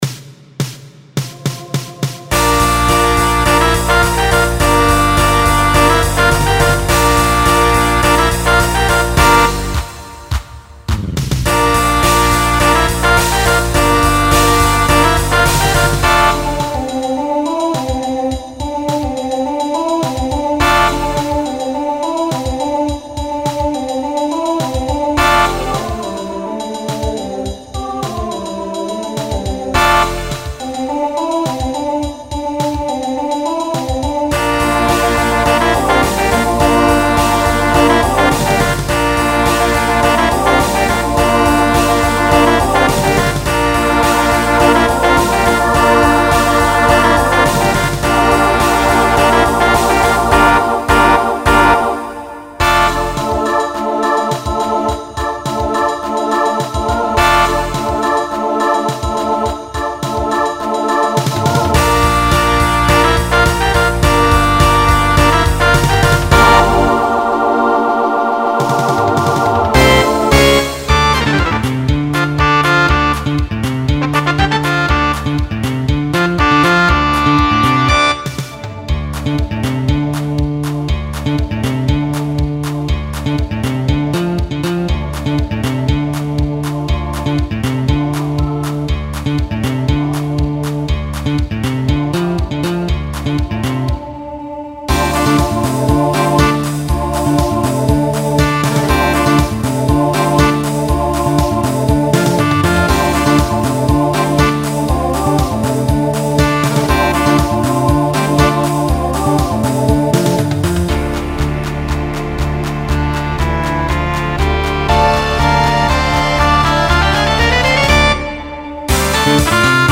Genre Pop/Dance , Rock Instrumental combo
Voicing SATB